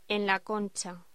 Locución: En la concha
voz